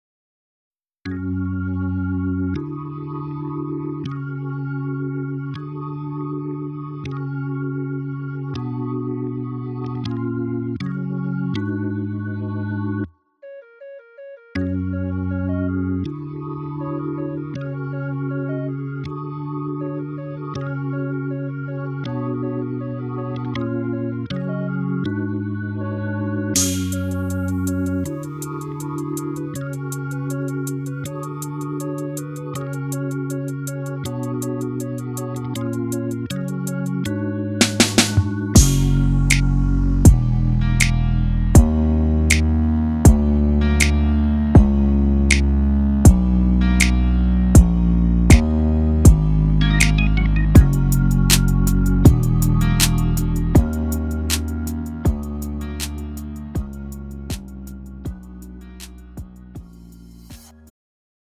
음정 원키 4:06
장르 가요 구분 Pro MR